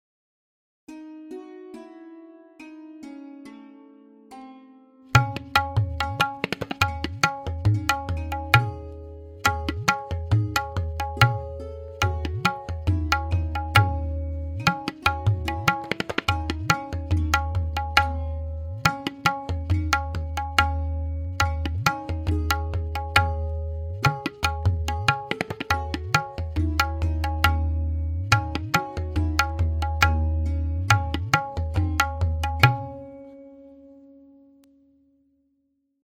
Full chakradar with lahra:
DK2-DhatidhagiNadhatirakita-S_Ck-Lehra.mp3